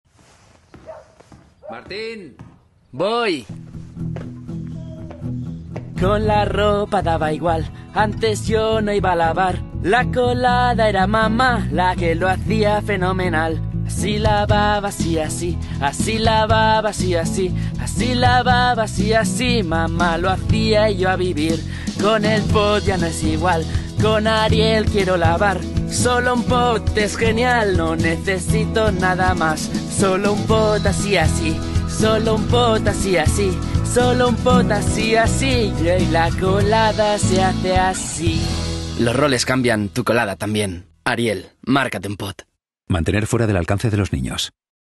sehr variabel
Jung (18-30)
Eigene Sprecherkabine
Commercial (Werbung)